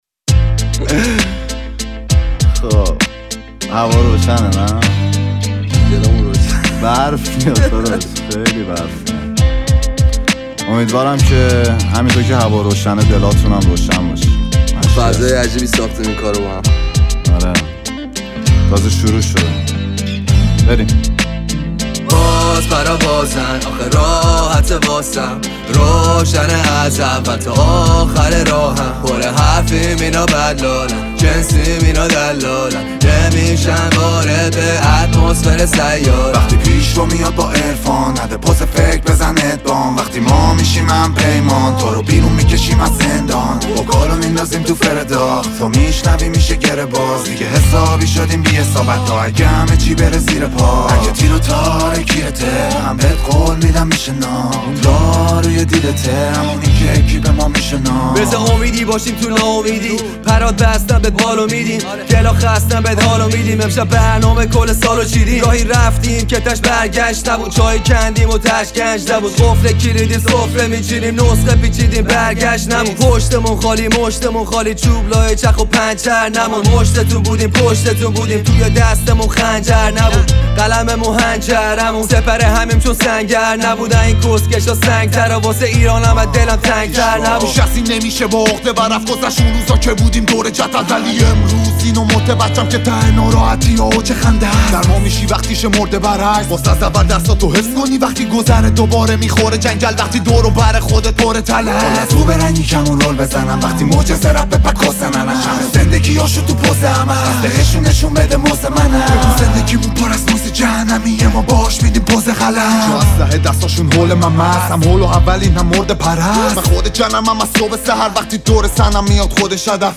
غمگین و احساسی